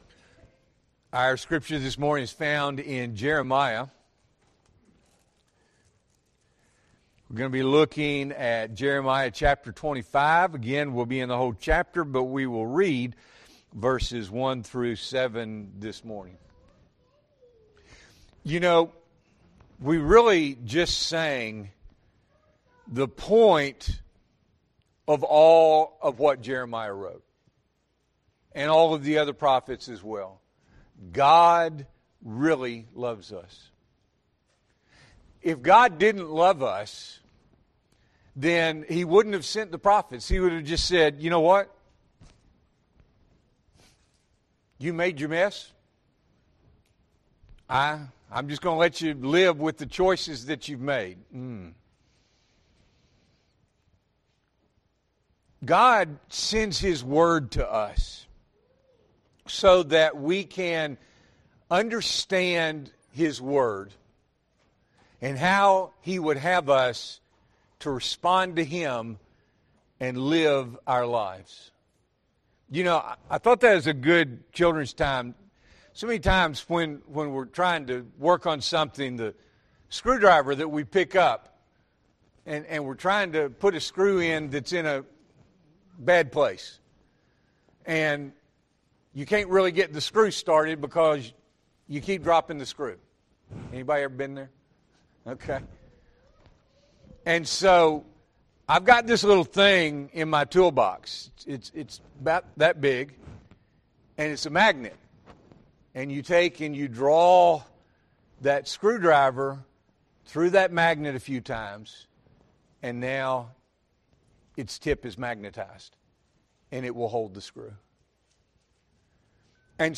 February 23, 2025 – Morning Worship